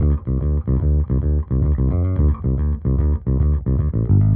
Index of /musicradar/dusty-funk-samples/Bass/110bpm
DF_JaBass_110-D.wav